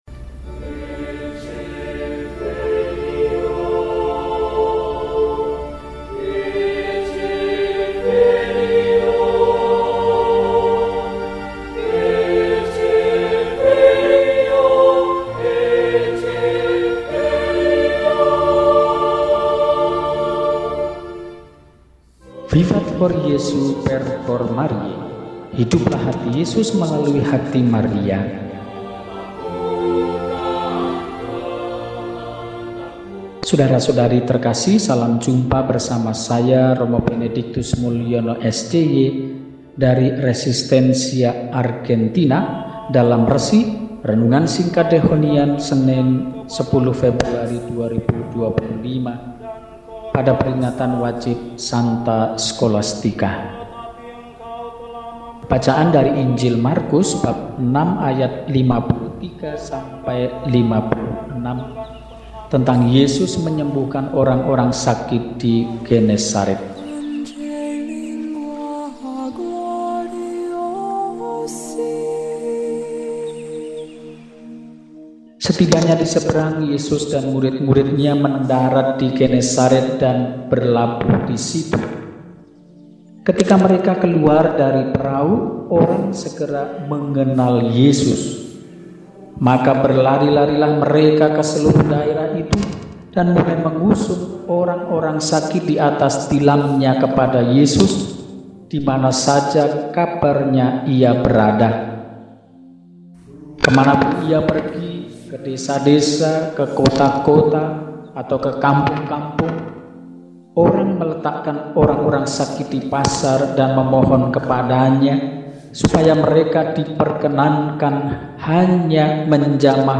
Senin, 10 Februari 2025 – Peringatan Wajib St. Skolastika, Perawan – RESI (Renungan Singkat) DEHONIAN